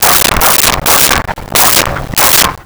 Dog Barking 01
Dog Barking 01.wav